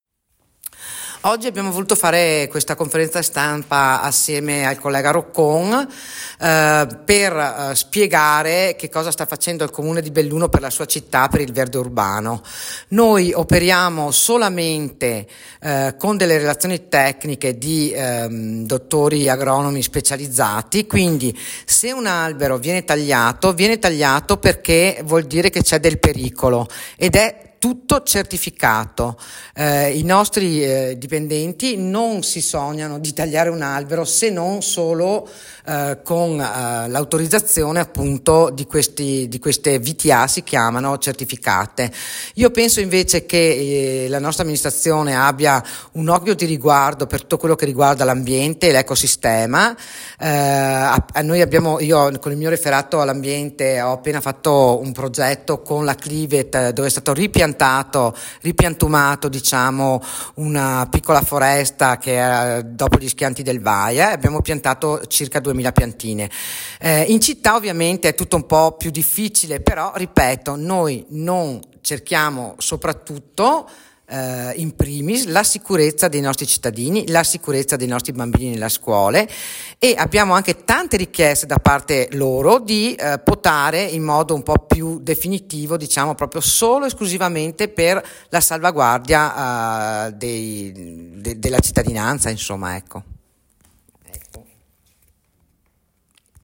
BELLUNO L’assessore Lorenza De Kunovich (referato all’ambiente) ha sentito il bisogno di spiegare il perchè gli alberti vengono tagliati “per la sicurezza” ribadisce l’assessore, anche su richiesta dei cittadini.
Assessore-Ambiente-Lorenza-De-Kunovich.mp3